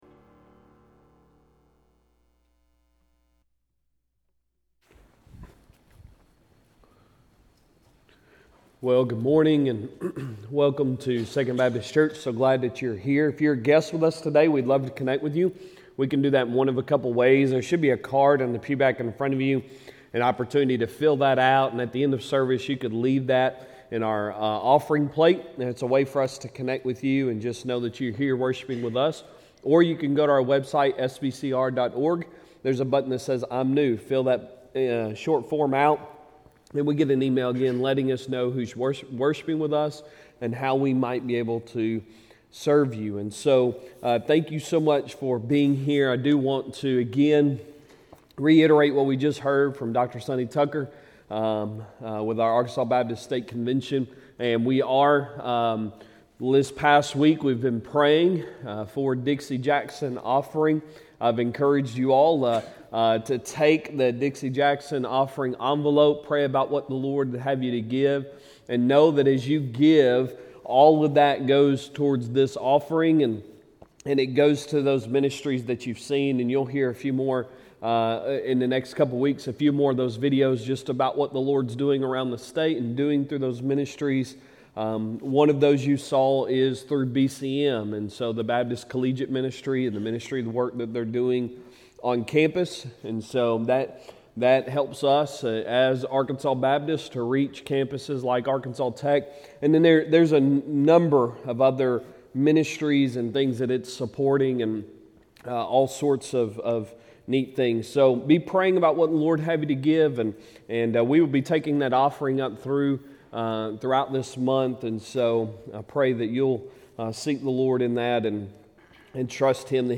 Sunday Sermon September 18, 2022